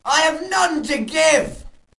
女人唱歌
单声道录音，使用廉价的动圈麦克风，Sound Blaster 16，Microsoft Sound Recorder。
标签： 圣诞 唱歌 歌曲